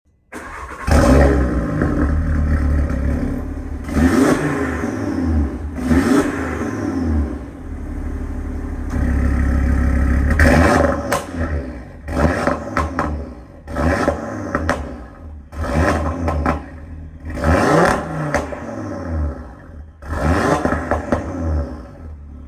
• DAZA Engine: Louder than post-2019 models
Listen to the DAZA Symphony
• RS Sports Exhaust (£1,000)
Coming equipped with the 2.5-litre TFSi 5-cylinder DAZA engine – one of the most sought after powerplants for Audi enthusiast due to that legendary five-cylinder growl, – it is not only louder than post-2019 models – due to a lack of sound muffling OPF filters – but it is also stronger and more tuneable too.
Audi-rs3-8v-nardo-grey-TTU-tune-510ps-OUM_Sound.mp3